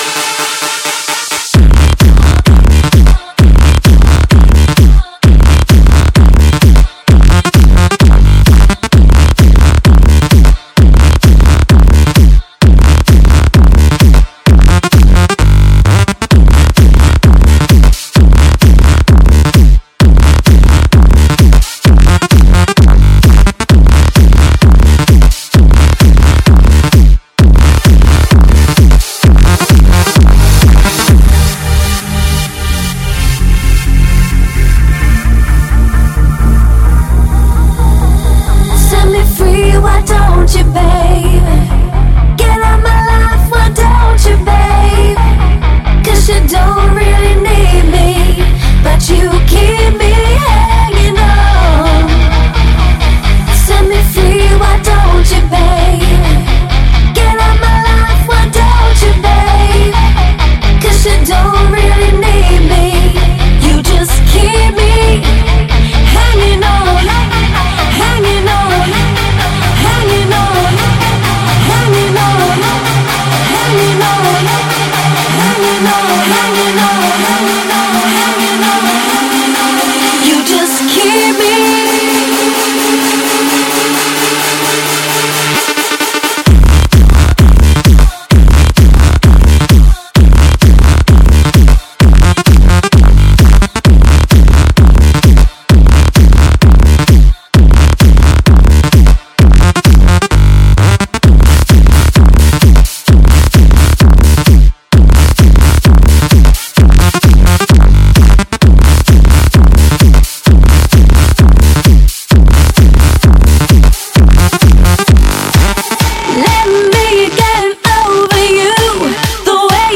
супер-пупер_мощные_басы_1
super_puper_moschnye_basy_1.mp3